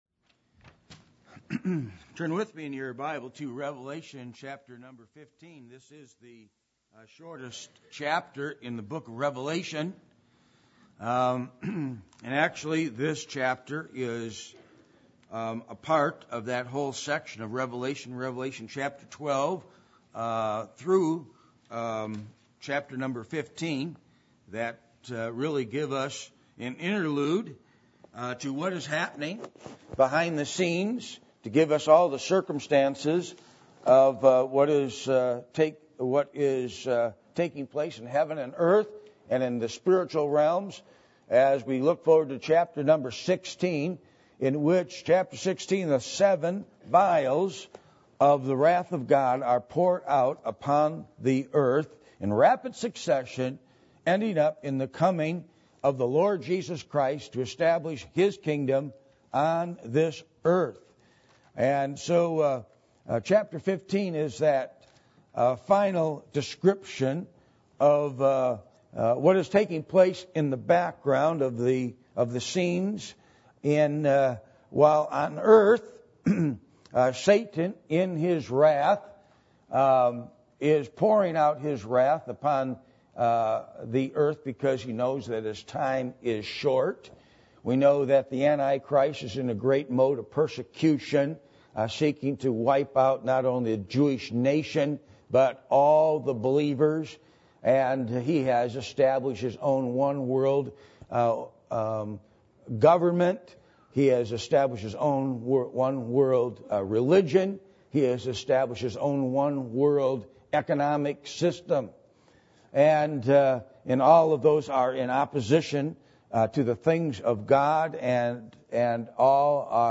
Passage: Revelation 15:1-8 Service Type: Sunday Morning